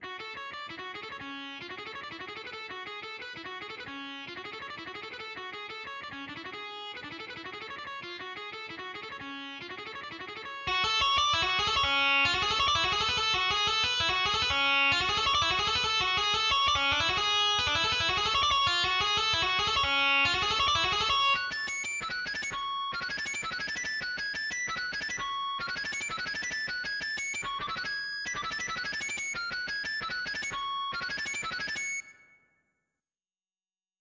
★オーバードライブギター
★ディストーションギター
★ギターハーモニクス